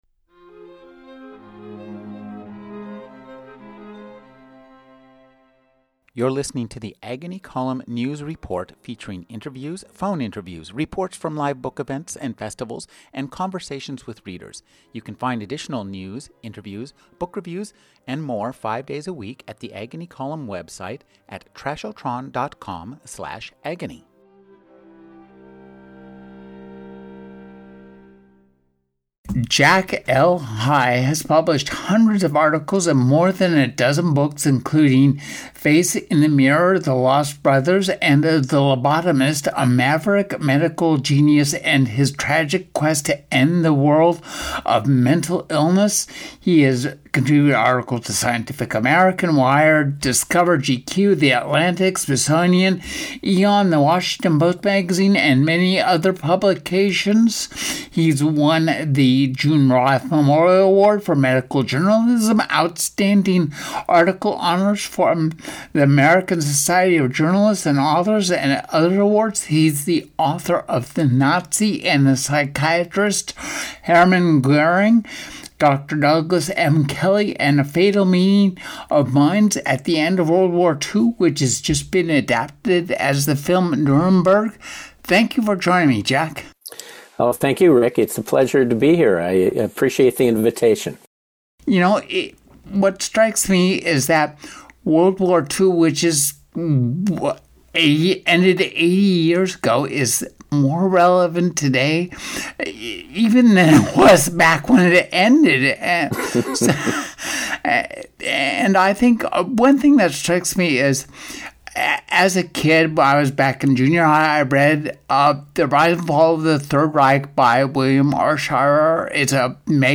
A 2026 Interview